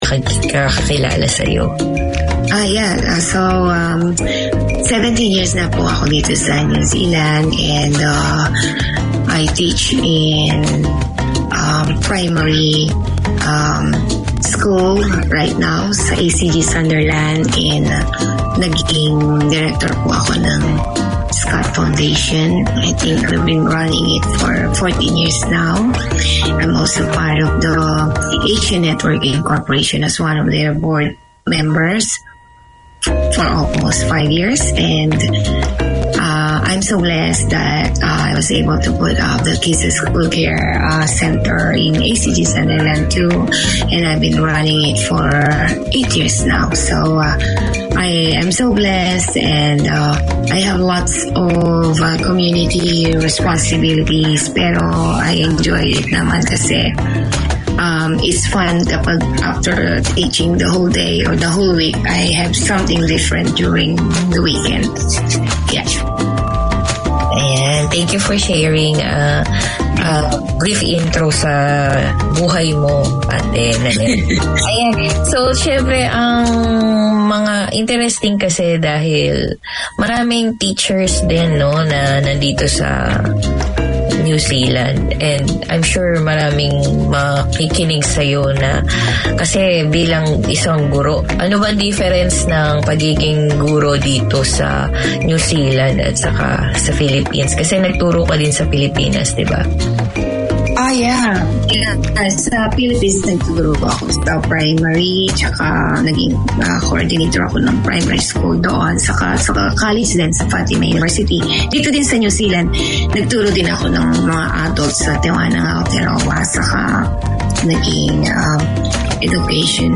Write in, phone in to this Filipino family and community show. There are topics for migrants, Mga Balitang Pilipino and news of local and international Filipino successes.
And don't miss Trabaho Agad and the ten minute free phone-in community noticeboard.